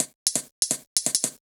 UHH_ElectroHatD_170-01.wav